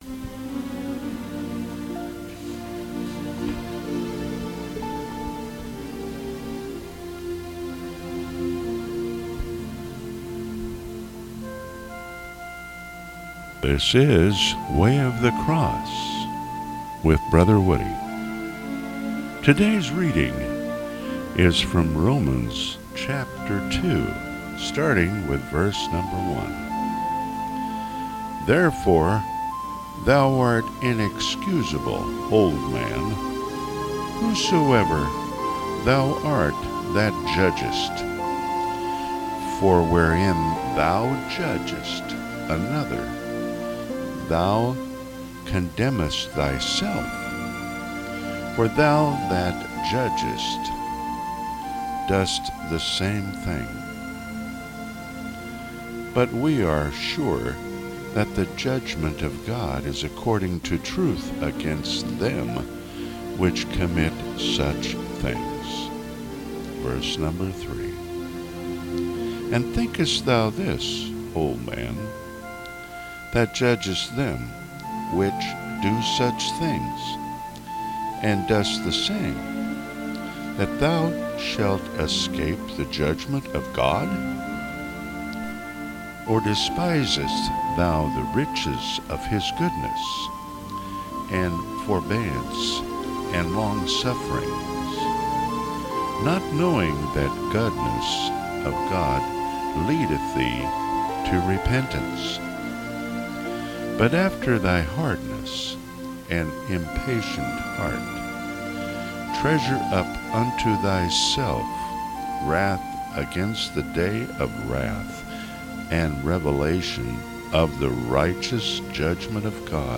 Bible readings